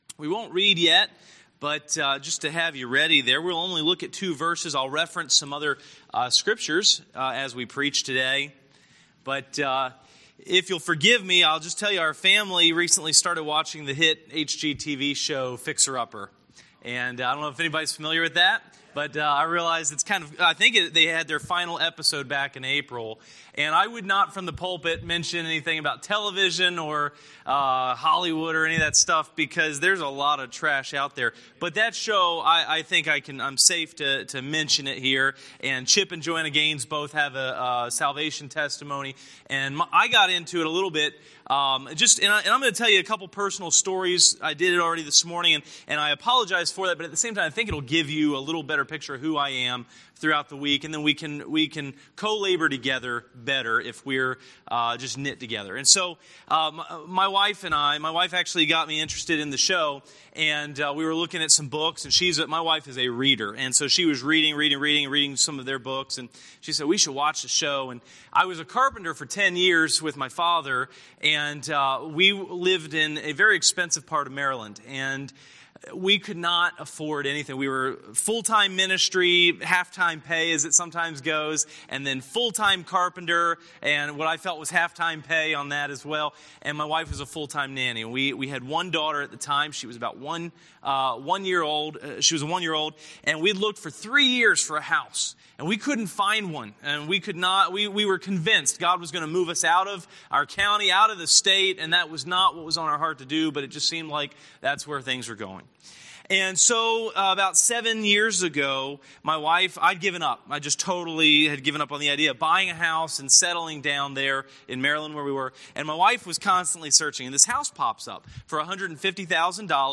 Sermons
Series: Guest Speaker